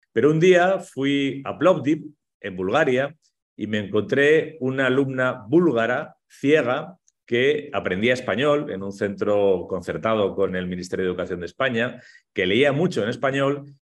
El pasado 19 de abril, los célebres autores Javier Cercas y Lorenzo Silva compartieron con los lectores y lectoras ciegos de los más de 30 Clubes de Lectura (braille y sonoro) que la ONCE tiene en el país una tarde en la que, ambos escritores conversaron y compartieron sus experiencias literarias en el ya tradicional encuentro literario online con motivo del Día del Libro.